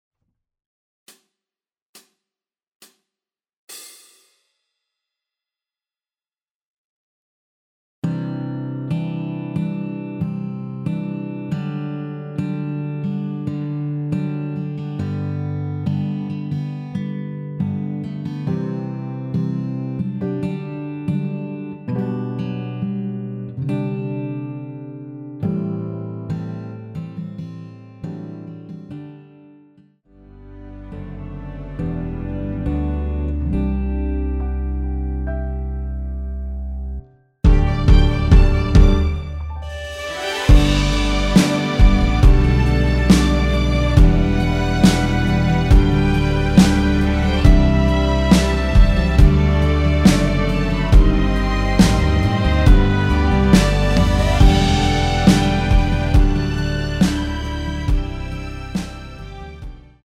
전주 없이 시작하는 곡이라서 시작 카운트 만들어놓았습니다.(미리듣기 확인)
원키에서(-1)내린 (1절앞+후렴)으로 진행되는 MR입니다.
앞부분30초, 뒷부분30초씩 편집해서 올려 드리고 있습니다.
중간에 음이 끈어지고 다시 나오는 이유는